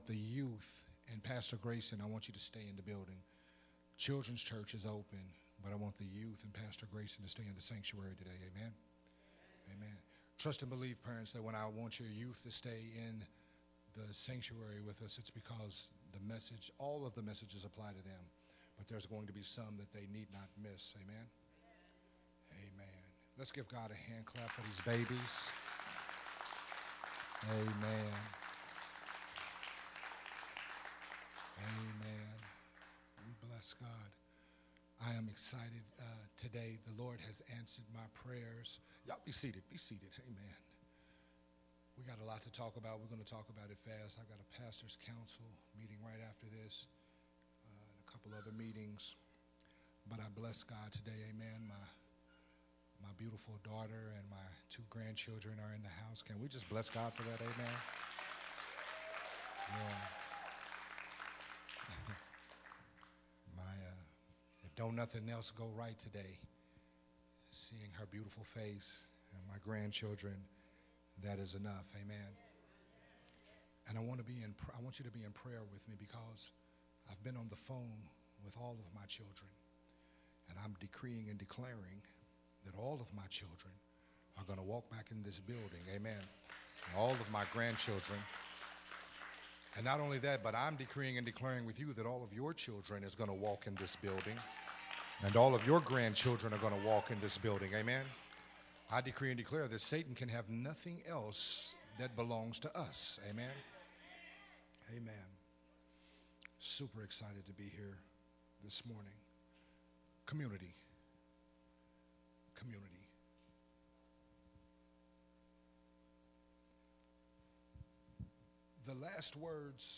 Part 4 of the sermon series
recorded at the Unity Worship Center